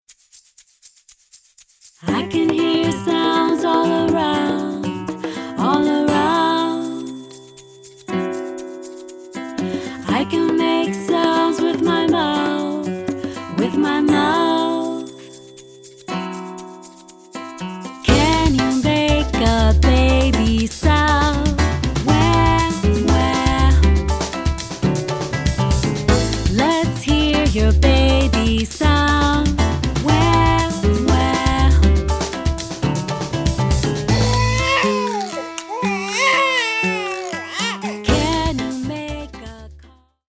upbeat songs